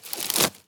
wood_tree_branch_move_11.wav